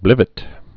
(blĭvĭt)